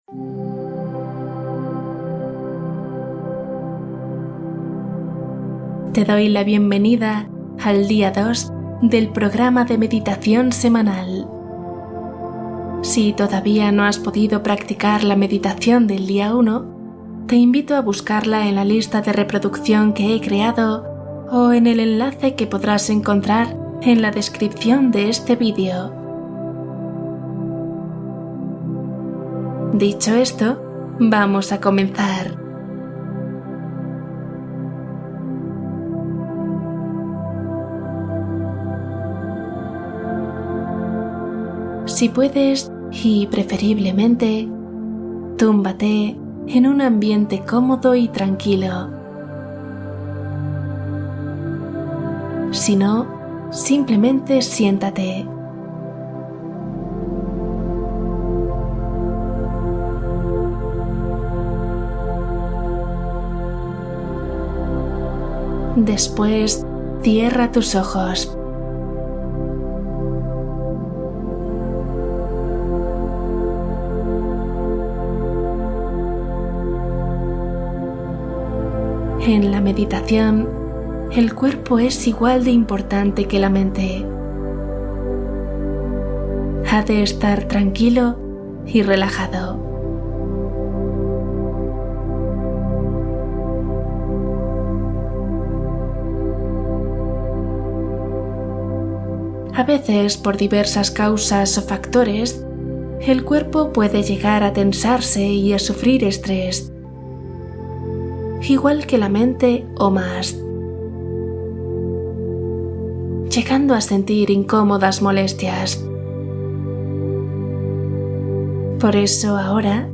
Relajar el cuerpo | Relajación profunda